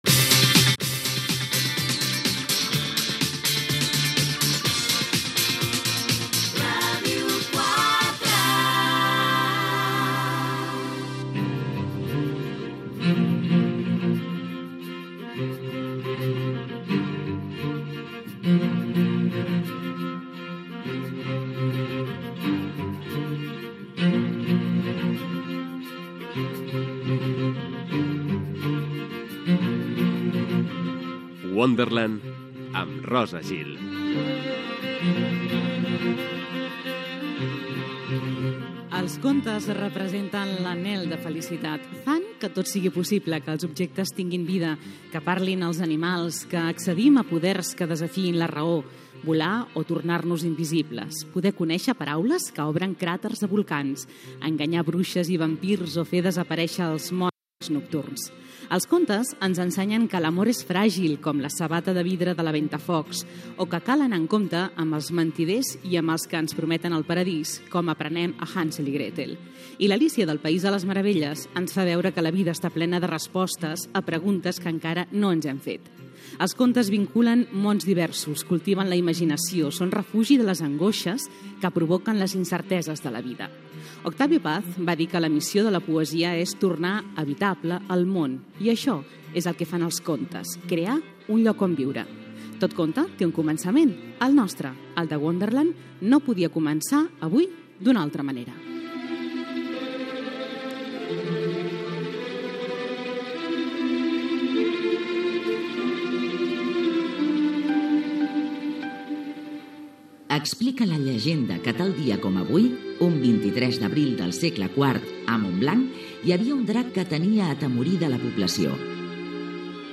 Indicatius de l'emissora i del programa
Gènere radiofònic Cultura